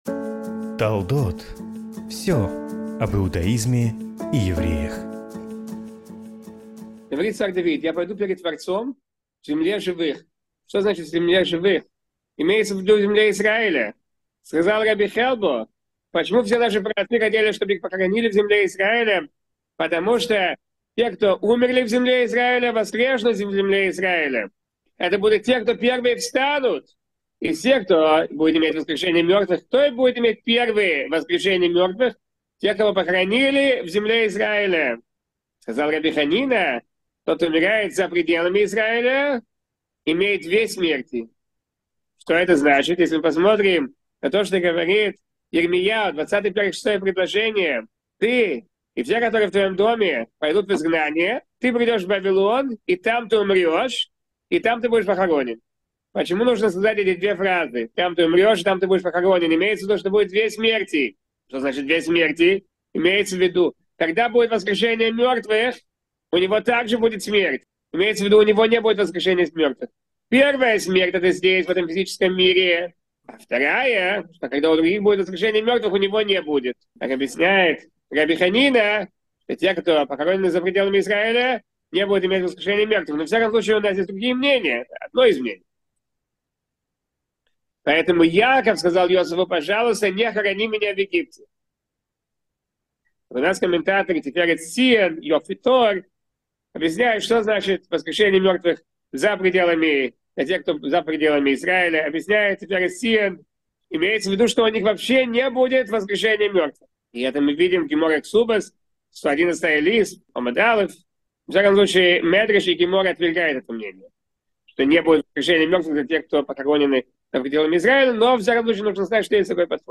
Почему умереть в Израиле: урок по главе Ваехи